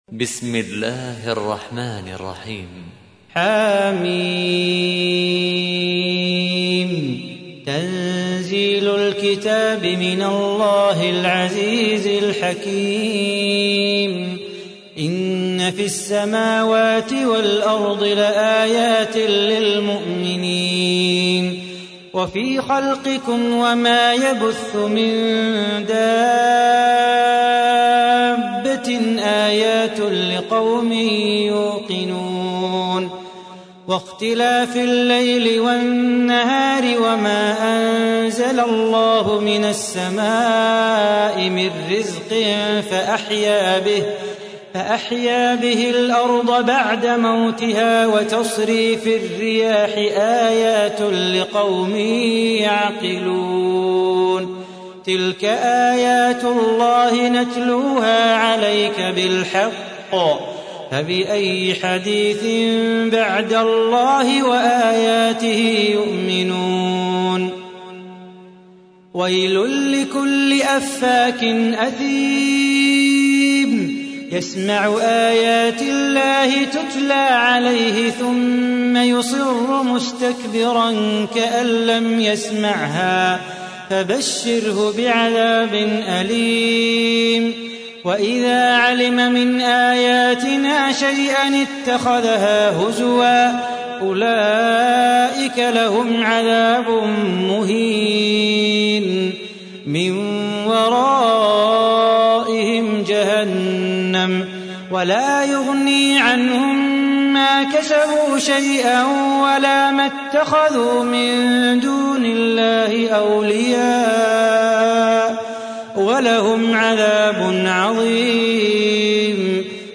تحميل : 45. سورة الجاثية / القارئ صلاح بو خاطر / القرآن الكريم / موقع يا حسين